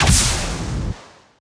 GUNArtl_Rocket Launcher Fire_05_SFRMS_SCIWPNS.wav